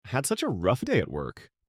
voice_in_conversation.mp3